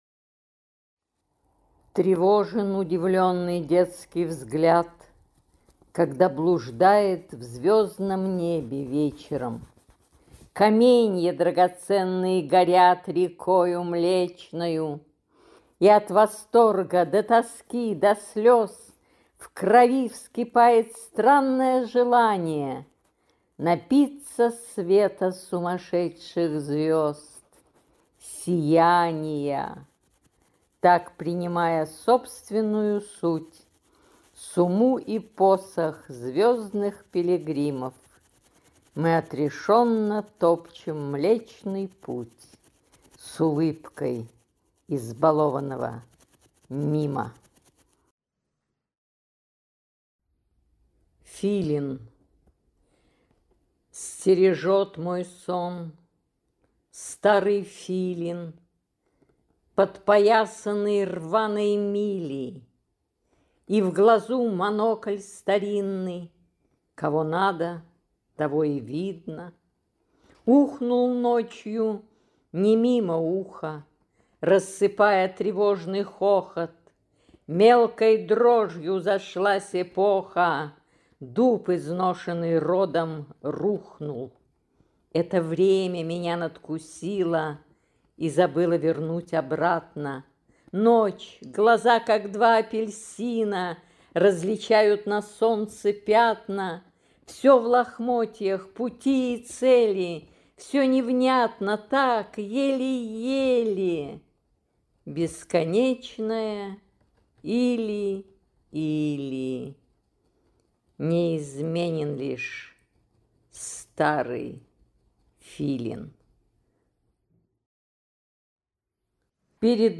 Стихи в исполнении автора (записи 2024-2025 г.)